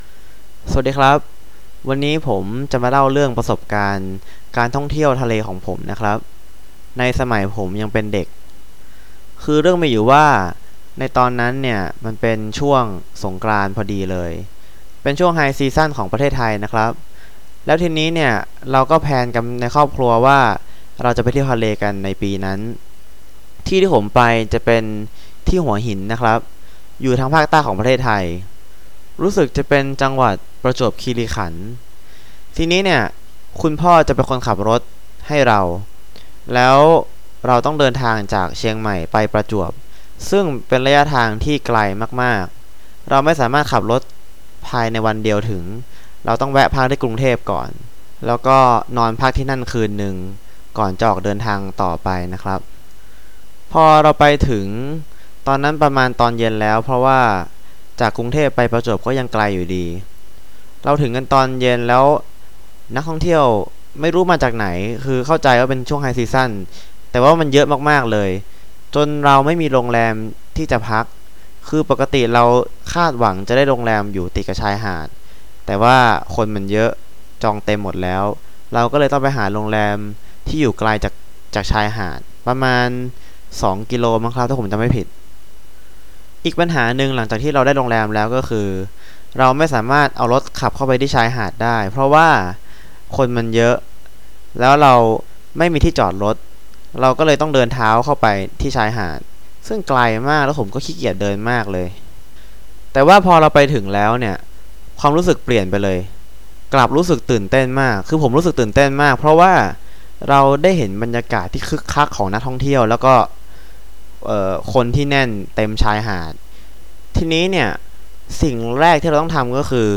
All recordings are unscripted, natural speech and 100% in Thai; they all come with a transcript.
Native speakers